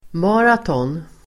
Ladda ner uttalet
Uttal: [m'a:ratån] Definition: löpning 42 kilometer